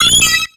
Cri d'Hypotrempe dans Pokémon X et Y.